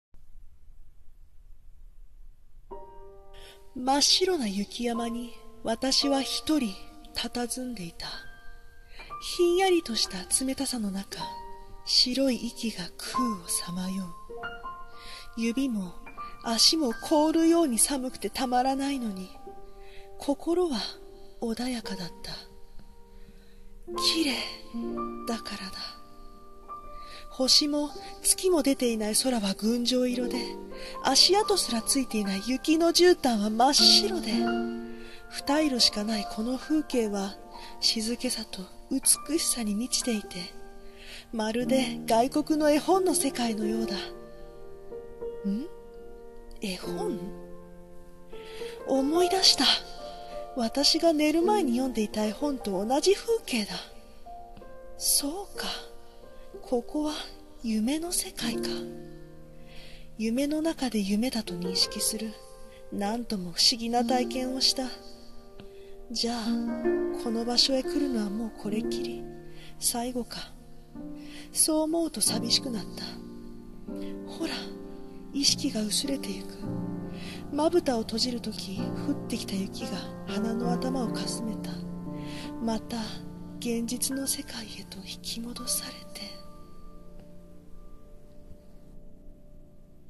【朗読台本】